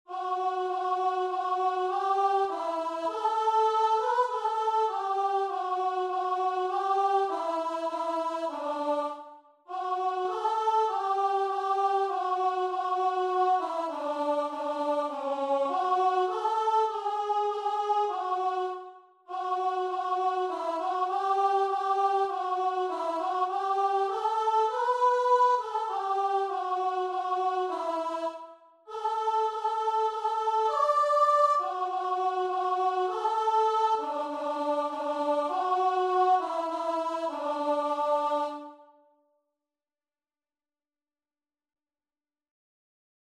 4/4 (View more 4/4 Music)
Classical (View more Classical Guitar and Vocal Music)